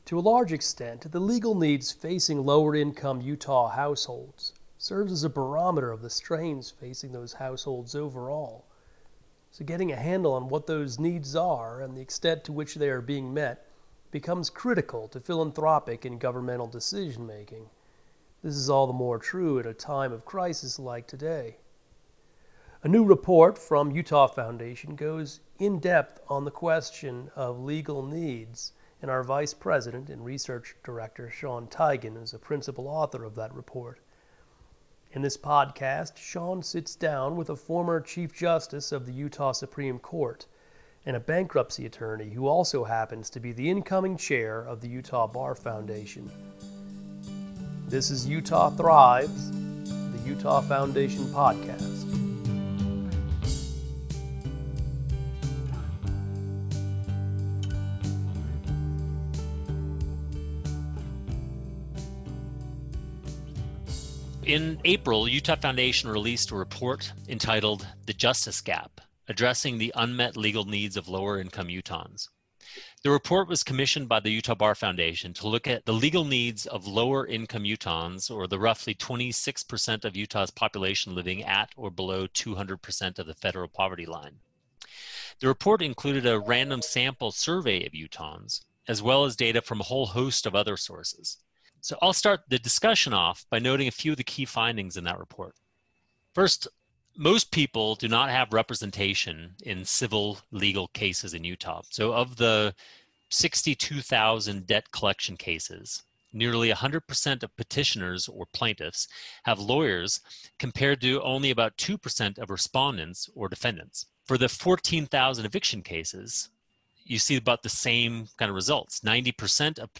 This episode of Utah Thrives pulls back the curtain to tell the hidden story of the legal and financial struggles of lower-income Utahns. We talk with retired Chief Justice Christine Durham